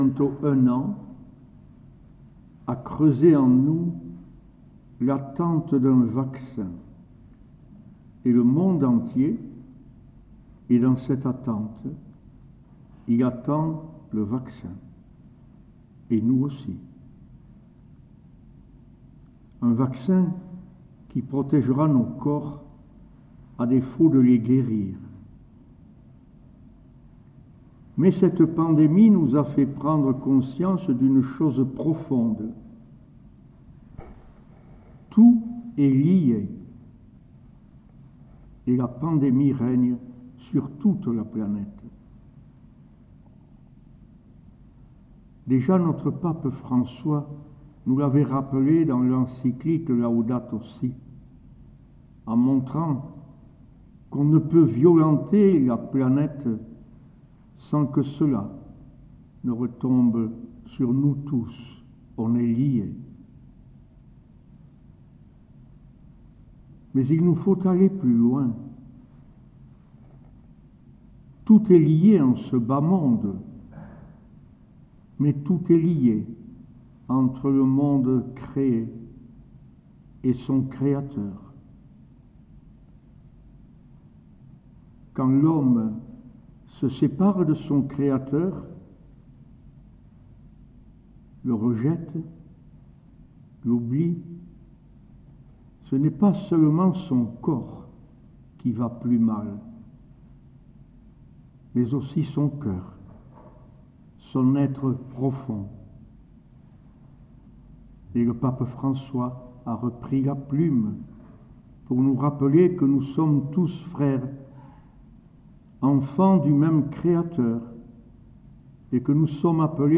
2ème Dimanche de l'Avent B